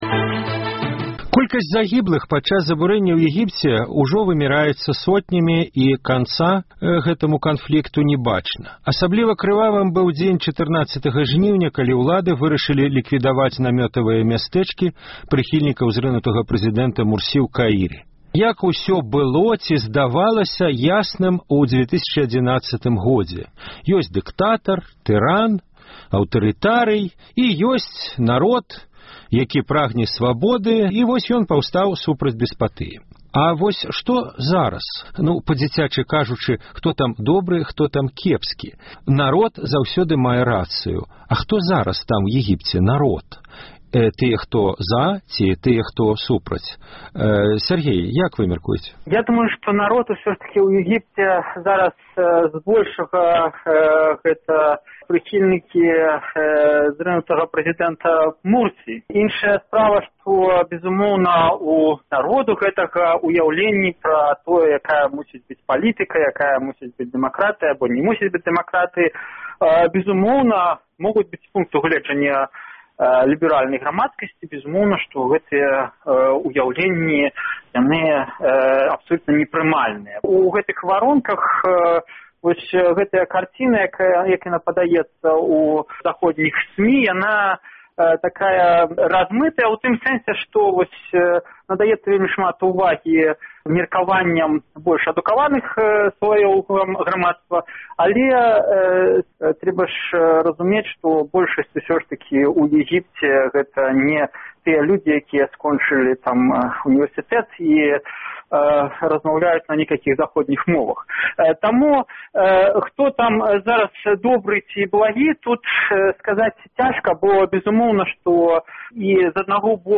Вядзе гутарку